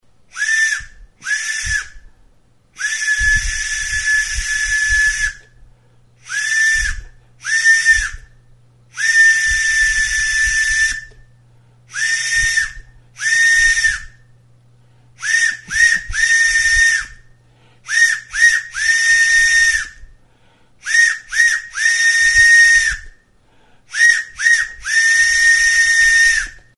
Aerophones -> Flutes -> Fipple flutes (one-handed)
Recorded with this music instrument.
Makal makilarekin egindako txulubita da.